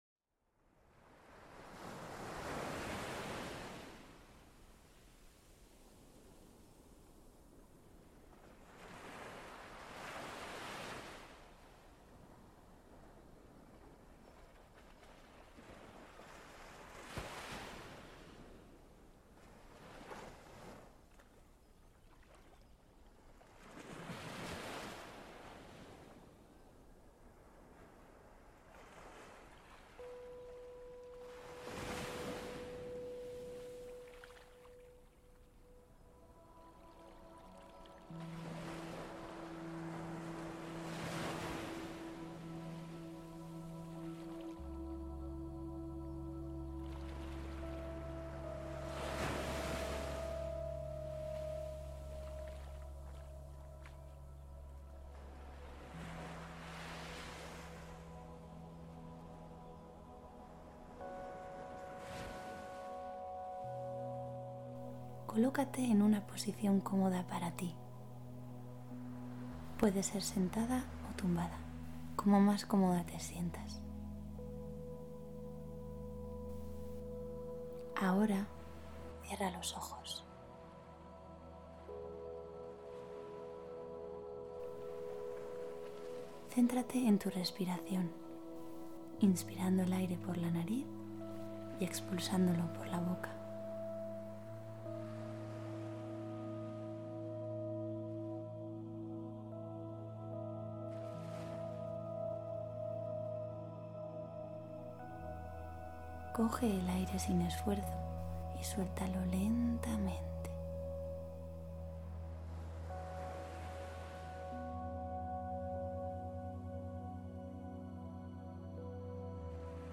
Esta es una sesión de relajación que he hecho para ti. Te acompaño con mi voz con el fin de que puedas descargar la tensión de los entrenamientos.
Sesión de relajación.